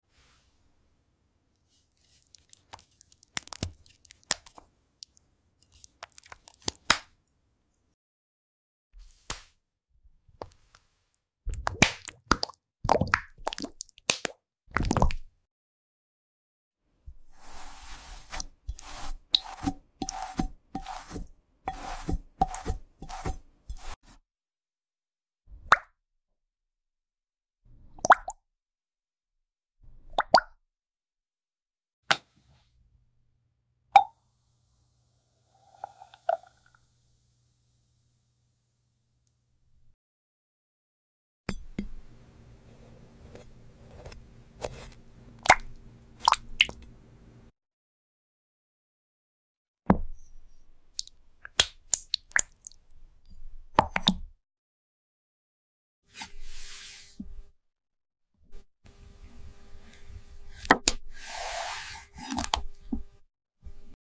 🍯 Honey ASMR – Part sound effects free download
Part 1 Thick, slow, and endlessly satisfying. Welcome to the sticky world of Honey ASMR – where every drip, stretch, and swirl delivers pure tingles. No talking. Just golden textures and deep, gooey sounds.